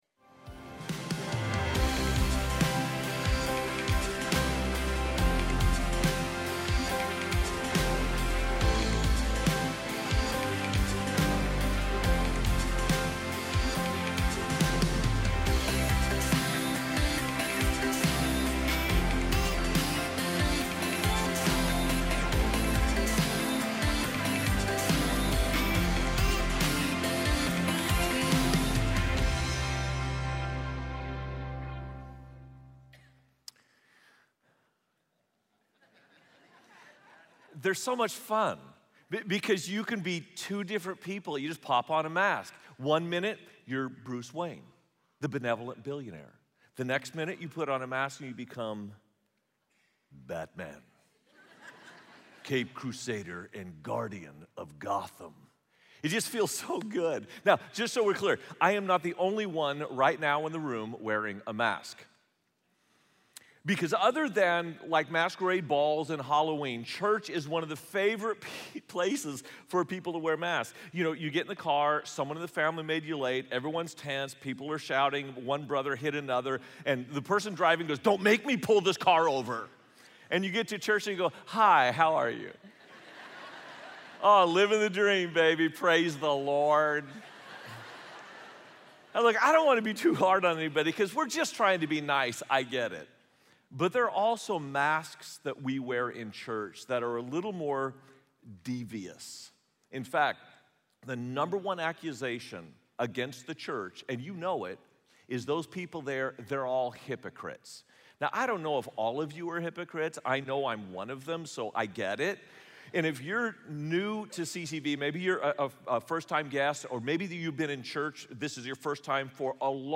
Missed church service this weekend or want to hear the message again?